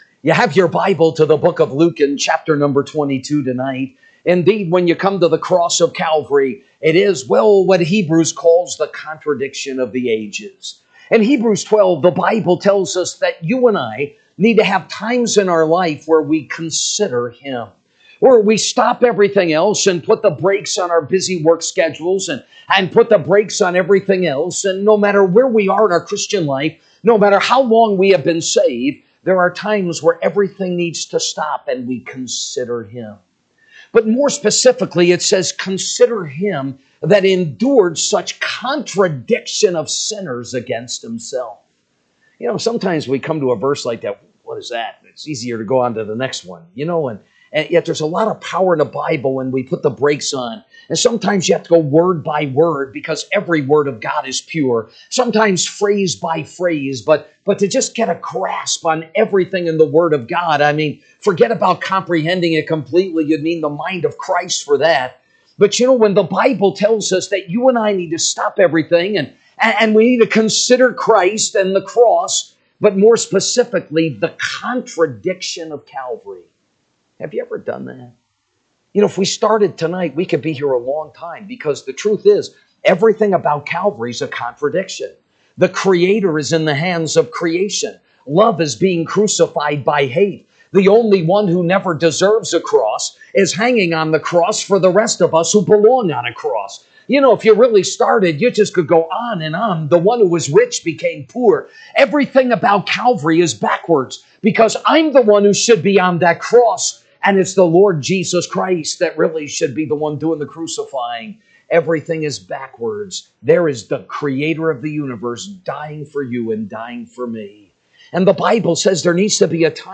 March 17, 2025 Tue. Spring Revival